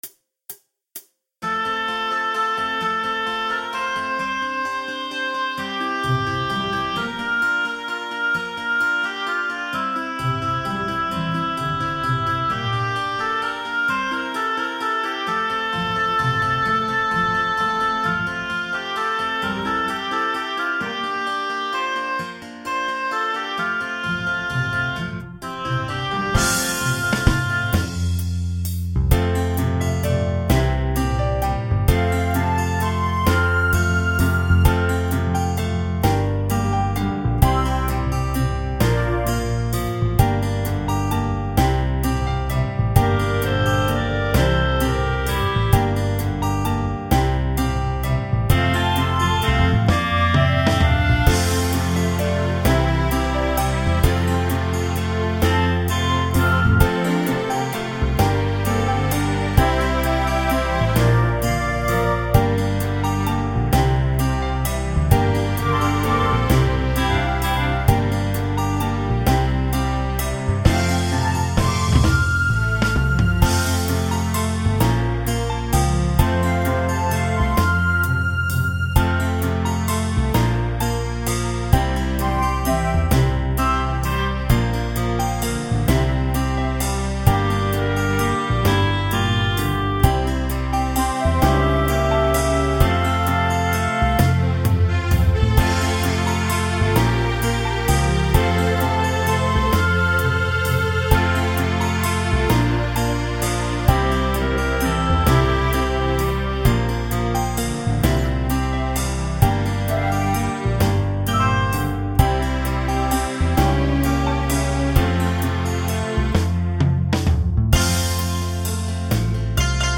سرآغاز-نور-بی-کلام.mp3